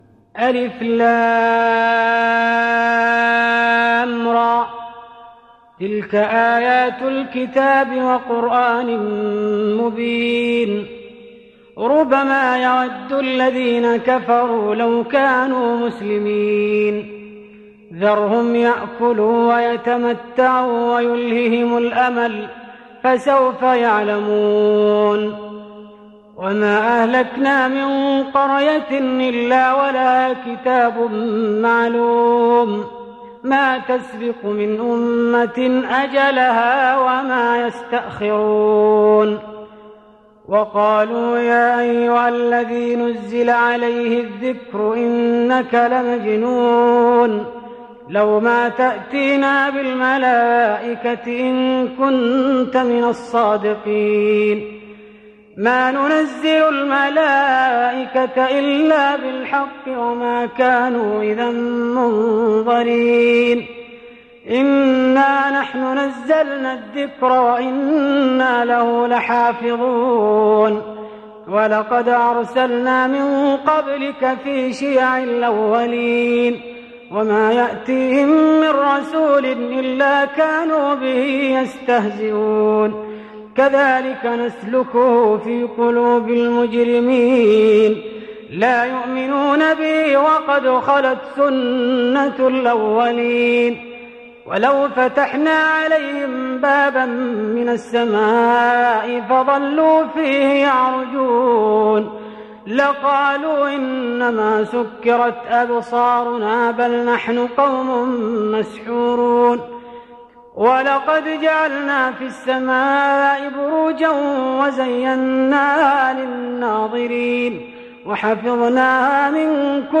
تهجد رمضان 1415هـ سورة الحجر - مريم - الواقعة Tahajjud Ramadan 1415H from Surah Al-Hijr and Maryam and Al-Waaqia > تراويح الحرم النبوي عام 1415 🕌 > التراويح - تلاوات الحرمين